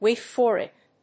アクセントWáit for it!